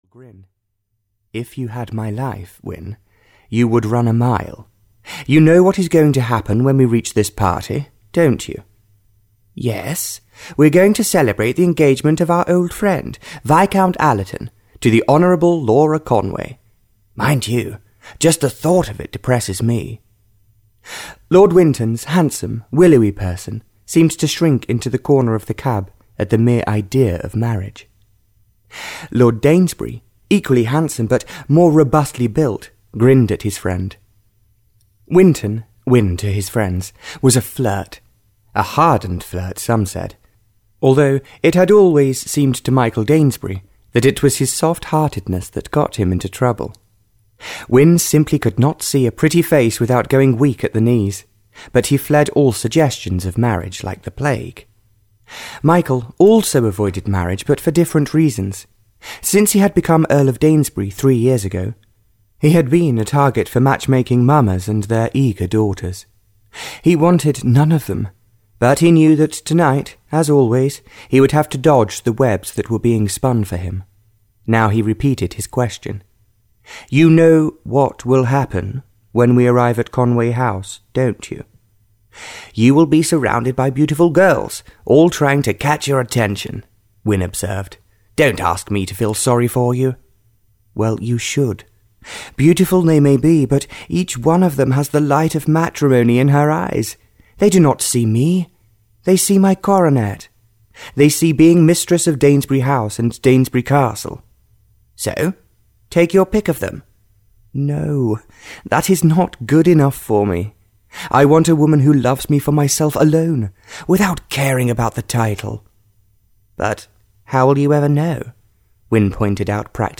Audio knihaRoyalty Defeated by Love (Barbara Cartland’s Pink Collection 22) (EN)
Ukázka z knihy